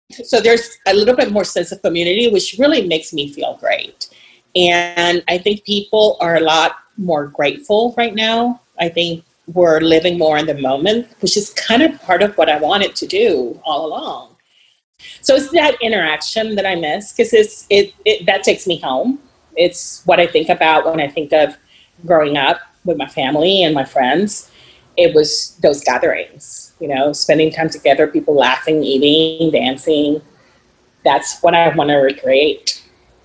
FULL TRANSCRIPT OF 2020 INTERVIEW